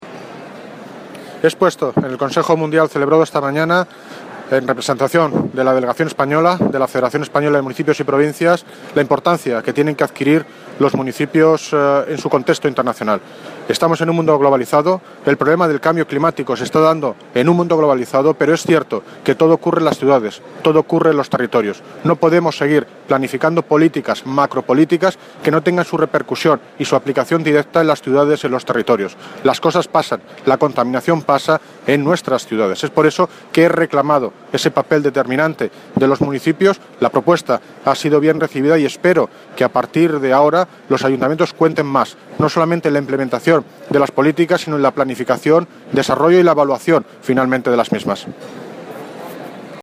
Audio - David Lucas (Alcalde de Móstoles) interviene en la Cumbre del Clima en Paris
Audio - David Lucas (Alcalde de Móstoles) interviene en la Cumbre del Clima en Paris.mp3